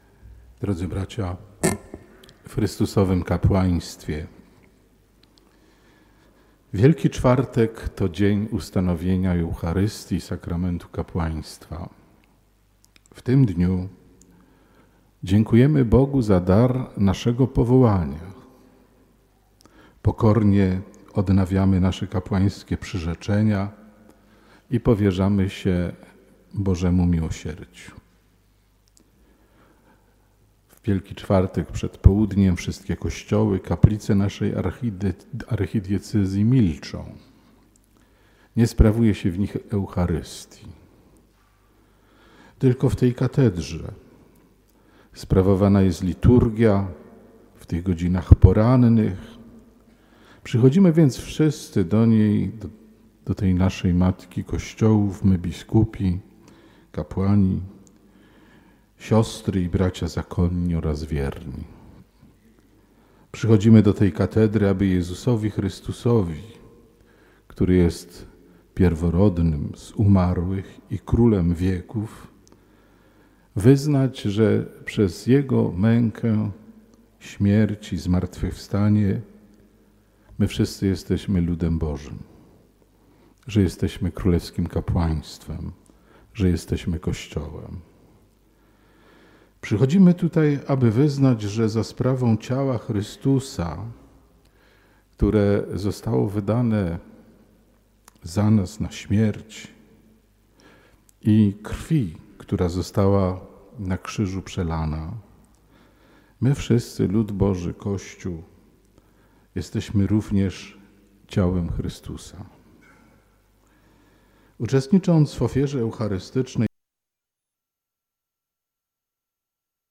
Przed południem, w Wielki Czwartek w Archikatedrze Wrocławskiej rozpoczęła się Msza Krzyżma świętego.
Homilia_abp_msza-krzyzma.mp3